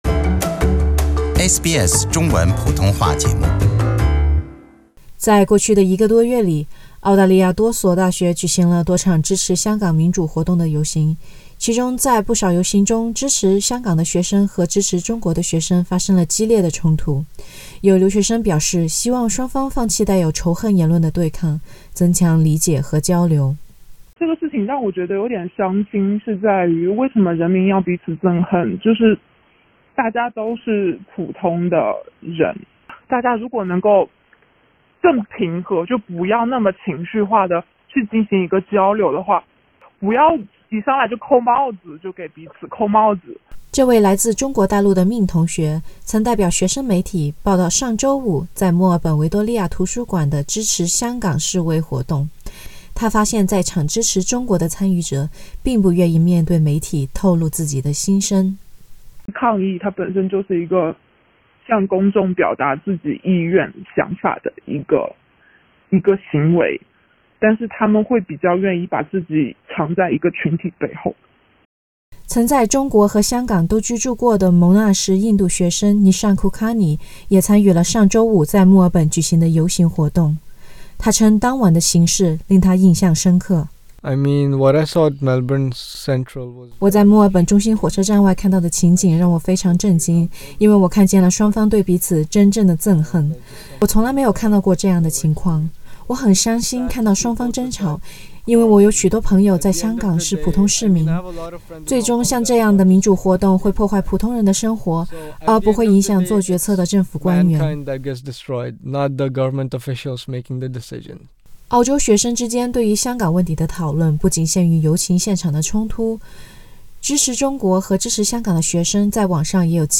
SBS普通话特约大学通讯员在莫纳什大学 (Monash University) 校园采访了多位不同背景的留学生，其中一位在中国大陆和香港都生活过的非华裔留学生还特别指出，诉诸暴力的民主活动只会破坏普通人的生活，而不会影响做决策的政府官员。